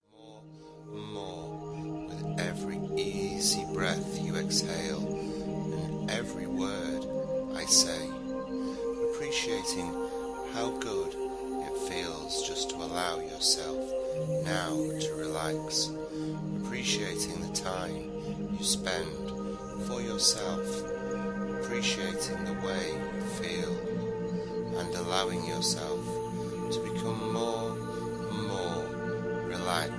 01 - Hypnosis - Stop Snacking